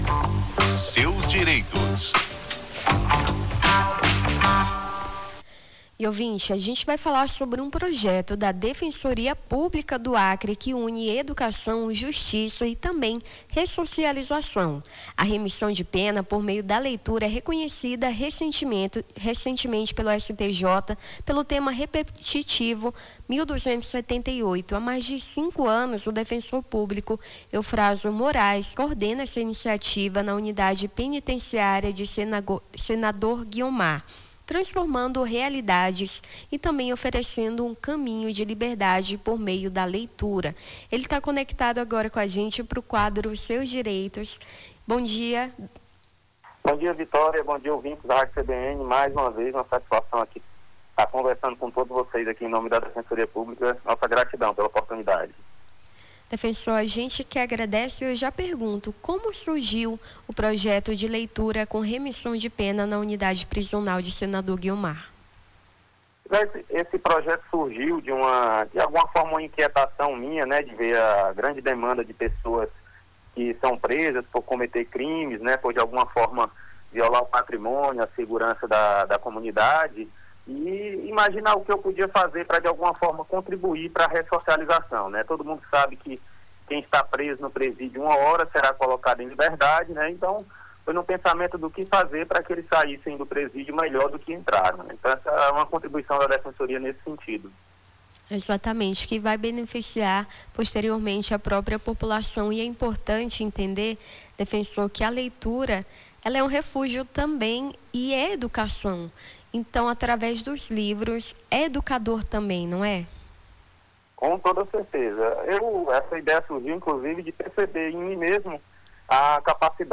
O Jornal da Manhã conversou sobre o projeto da Defensoria Pública do Acre que busca a remissão de pena por meio da leitura